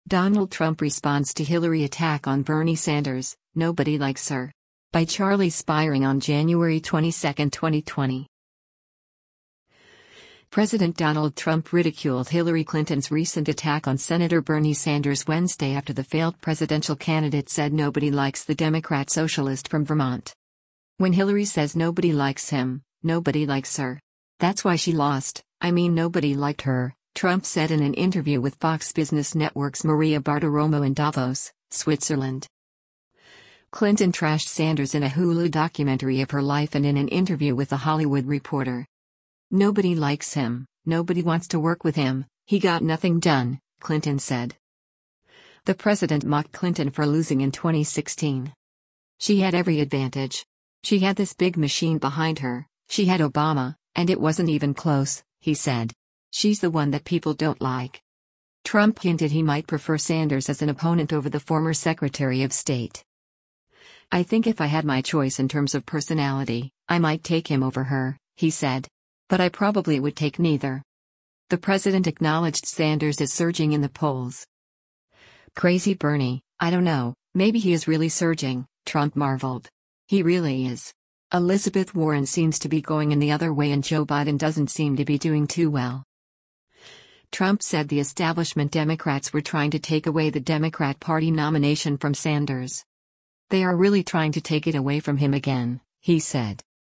“When Hillary says nobody likes him, nobody likes her. That’s why she lost, I mean nobody liked her,” Trump said in an interview with Fox Business Network’s Maria Bartiromo in Davos, Switzerland.